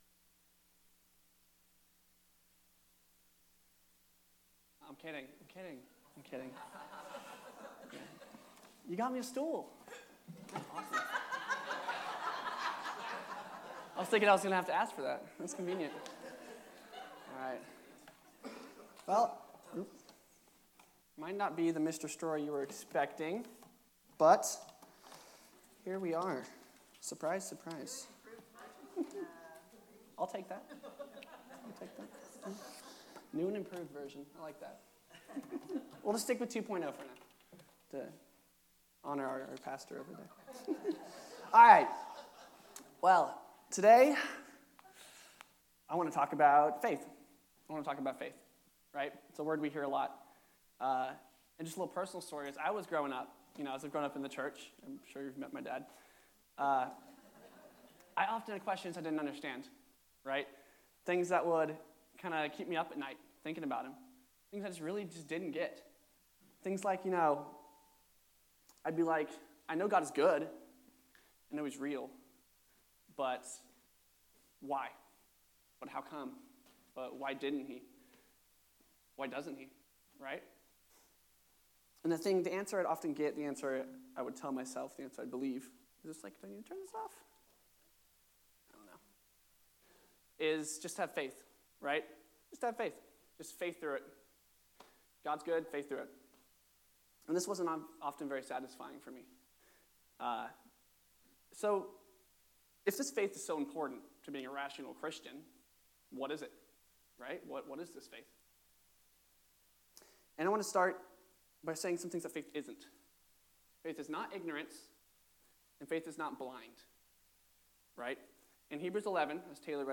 Sermons , Youth Sunday